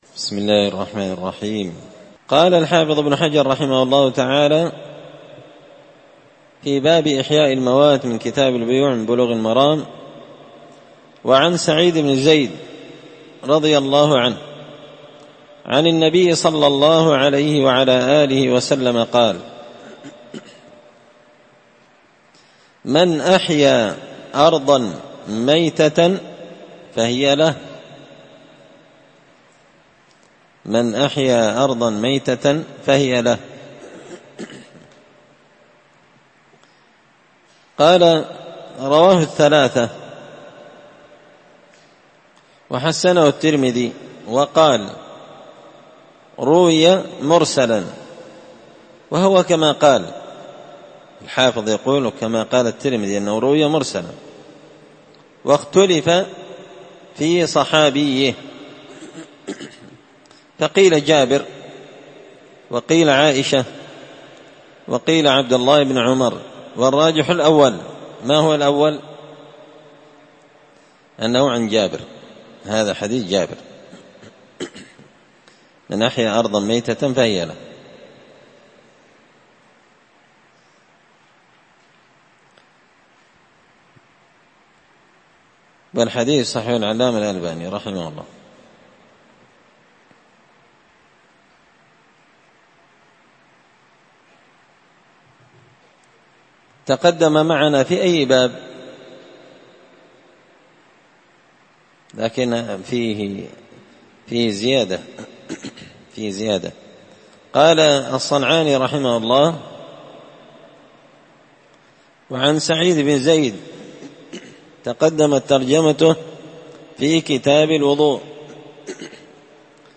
مسجد الفرقان_قشن_المهرة_اليمن